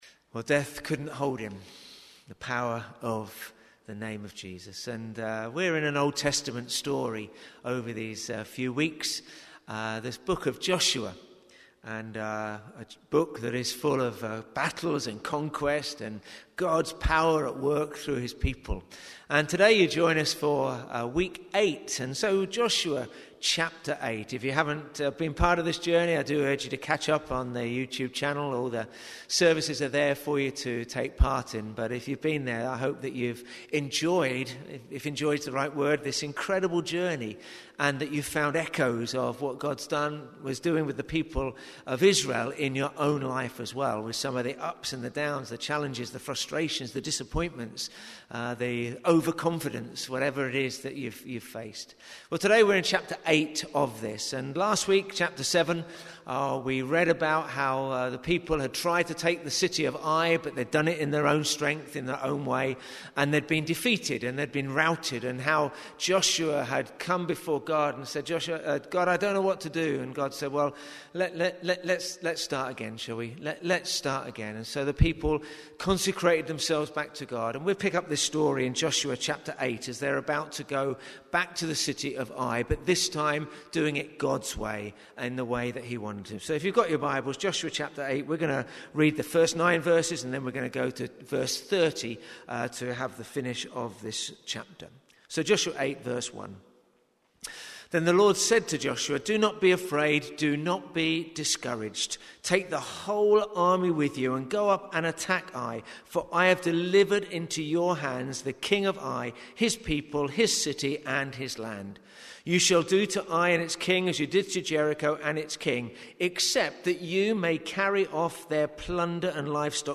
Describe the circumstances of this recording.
NBC-Worship-6th-June-2021.mp3